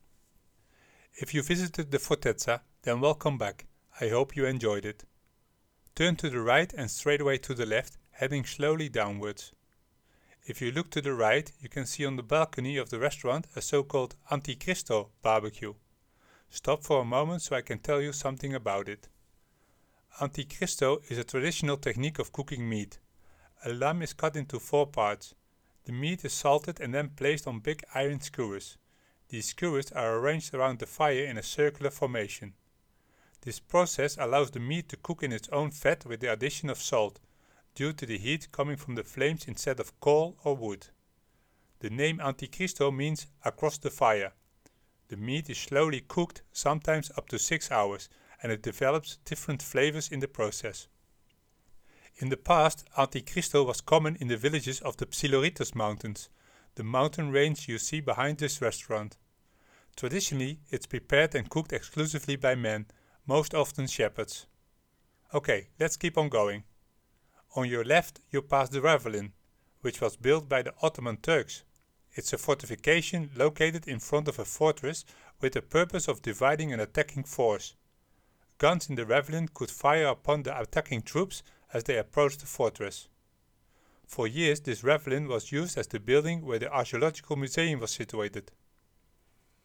It’s like having a guide or hostess sitting next to you in the passenger seat!
Your audio guide explains what it’s used for; Antikristo is a traditional method of preparing meat on Crete. This technique allows the meat to cook in its own fat and salt, utilizing the heat from the flames rather than coals.